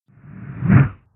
Откройте для себя удивительные звуки бабочек — легкое порхание, едва уловимый шелест крыльев и другие природные оттенки.
Звуки бабочек: Медленный взмах крыльев бабочки